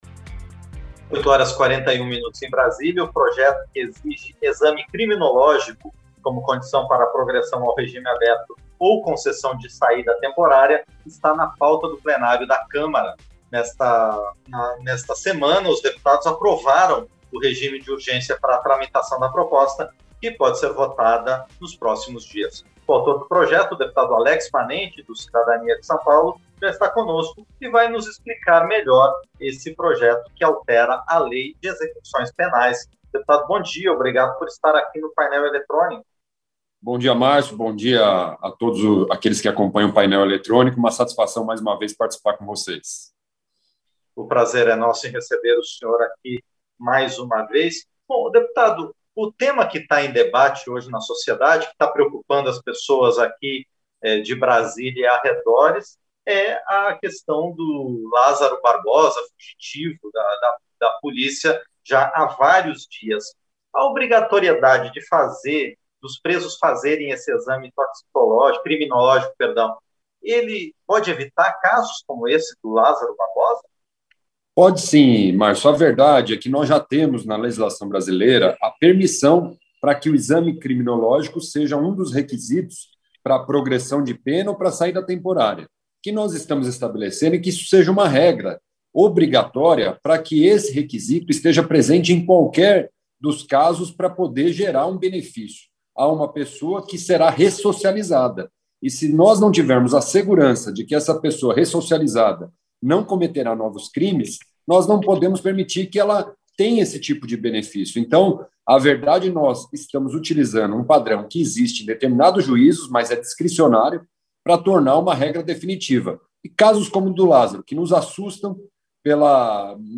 • Entrevista - Dep. Alex Manente (Cidadania-SP)
Programa ao vivo com reportagens, entrevistas sobre temas relacionados à Câmara dos Deputados, e o que vai ser destaque durante a semana.